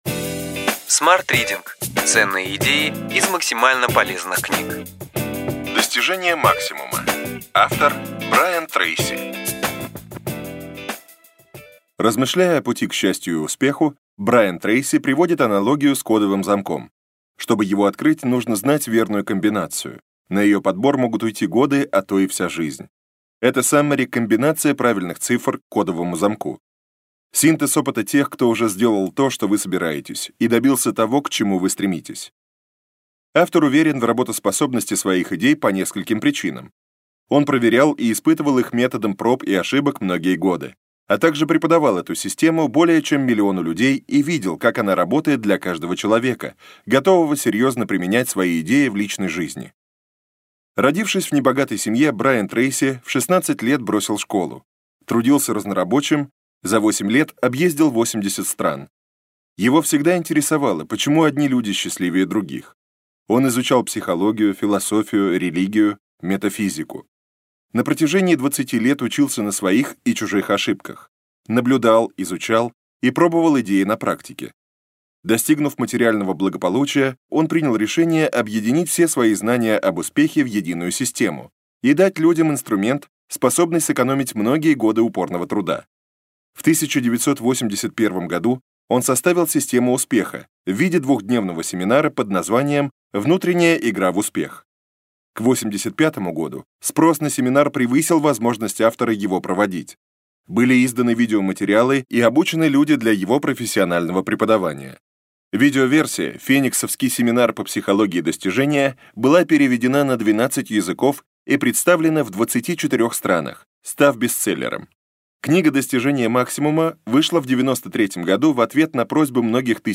Аудиокнига Ключевые идеи книги: Достижение максимума.